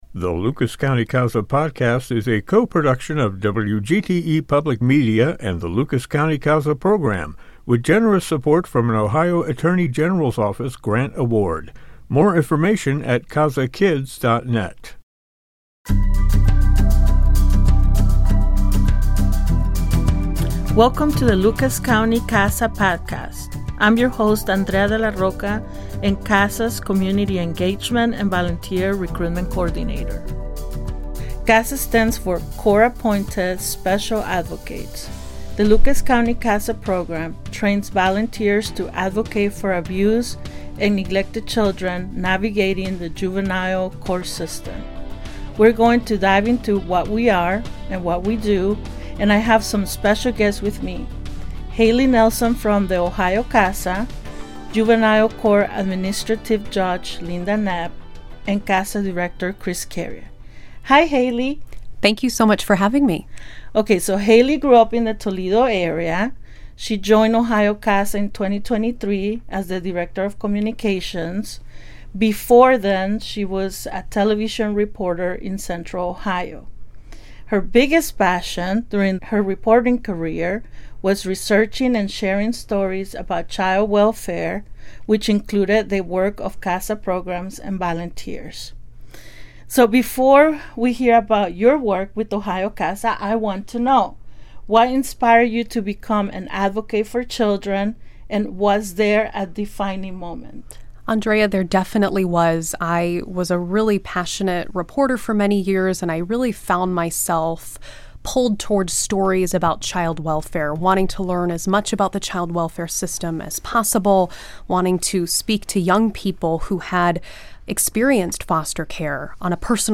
We break it down for you, with special guests